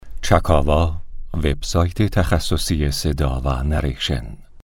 tablighati.mp3